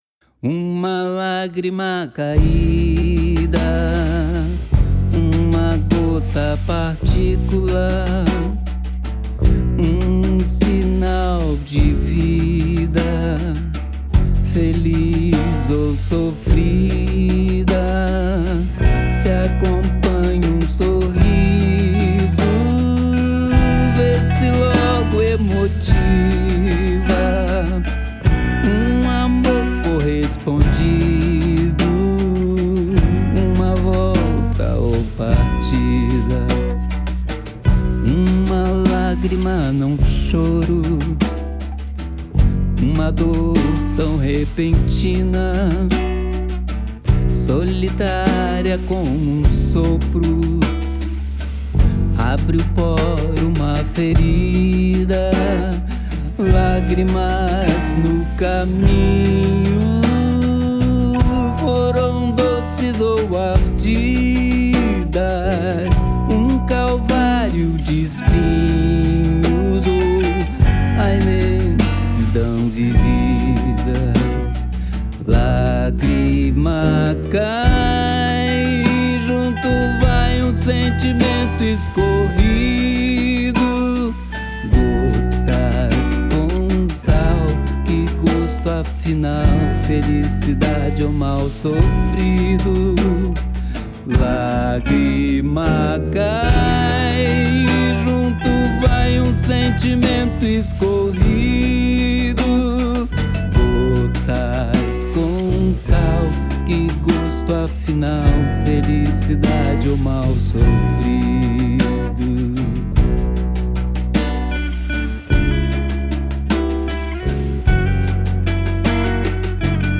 EstiloBlues